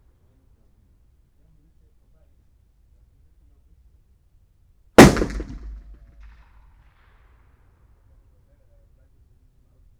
Environmental
Streetsounds
UrbanSoundsNew / 01_gunshot /shot556_151_ch01_180718_164102_15_.wav